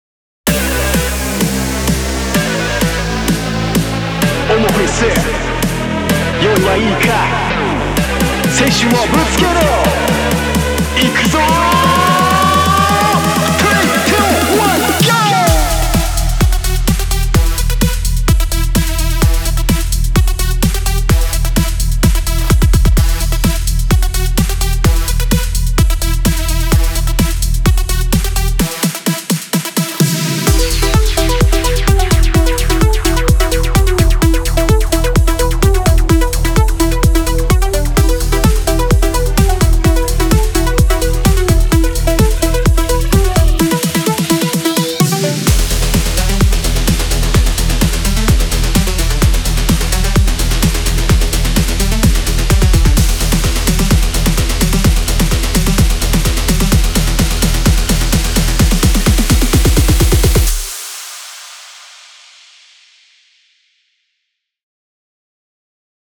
OMOFES_BPM130
OMOFES_BPM130.wav